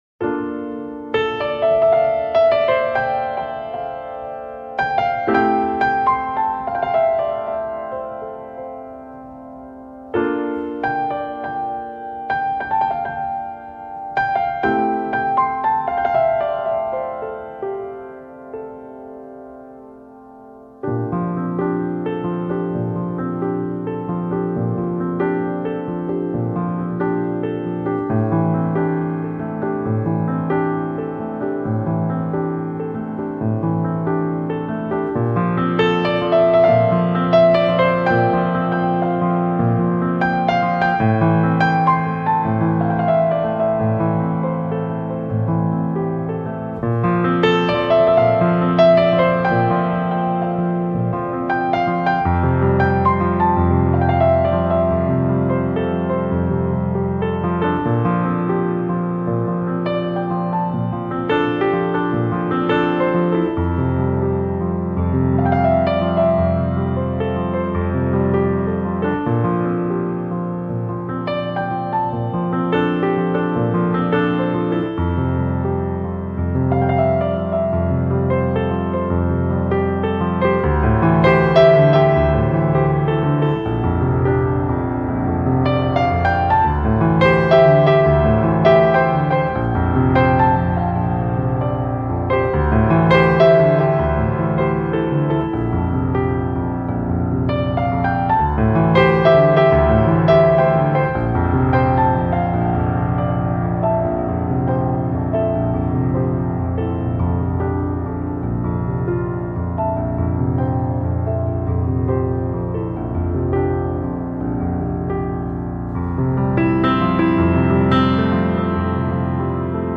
Жанр: New Age, Piano
迤邐滿園女人心事的鋼琴詩文集